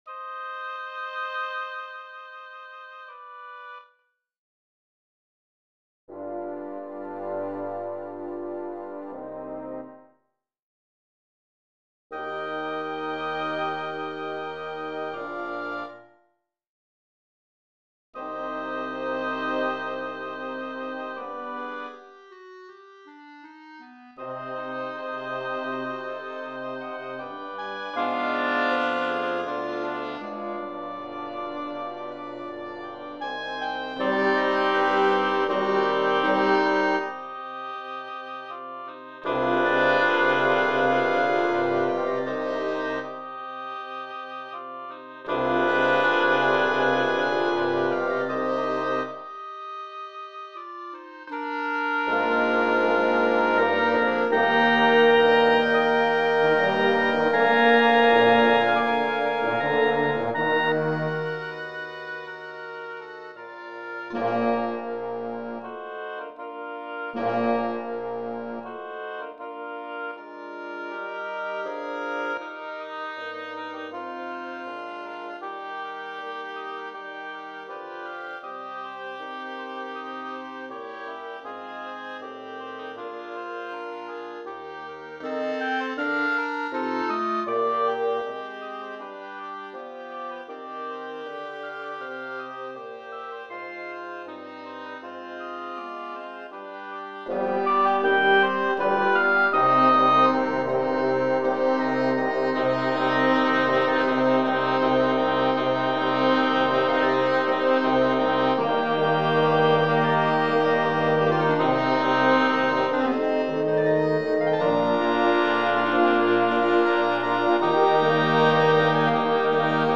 2 Hautbois, 2 Clarinettes Sib, 2 Cors et 2 Bassons